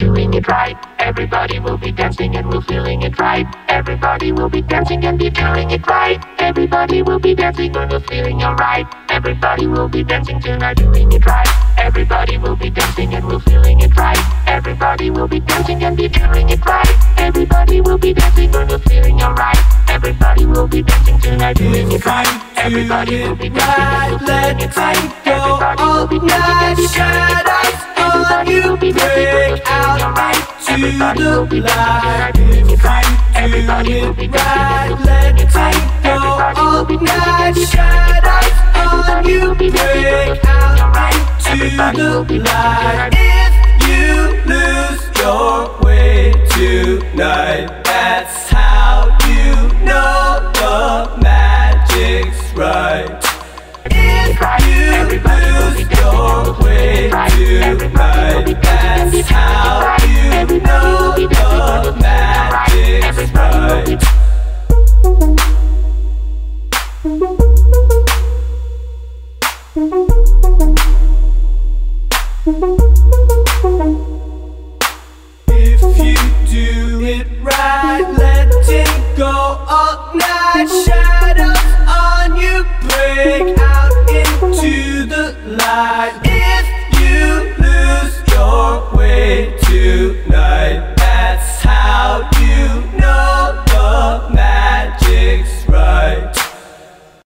Audio QualityMusic Cut